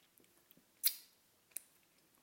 吃水果 咀嚼声
描述：吃西瓜 声音比较脆
标签： 咀嚼 吃水果
声道立体声